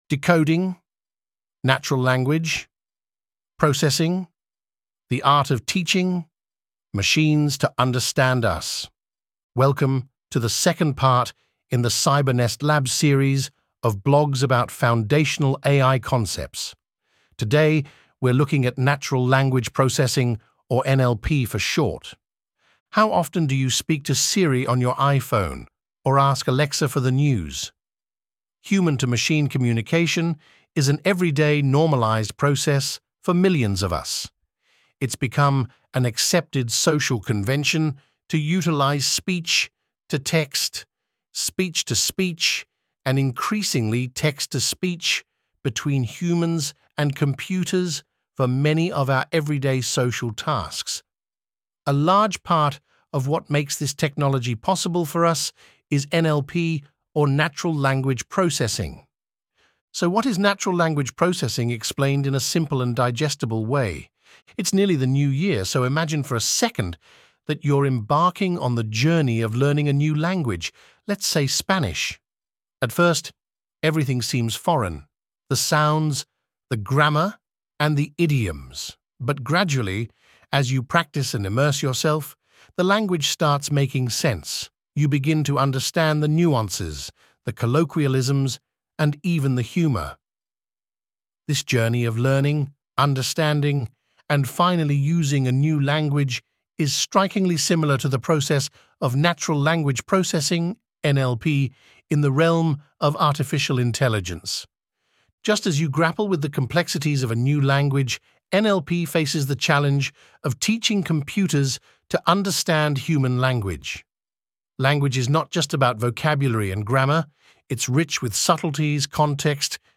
Made with: Read Aloud Decoding Natural Language Processing: The Art of Teaching Machines to Understand Us!